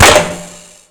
bolt_skewer1.wav